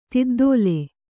♪ tiddōle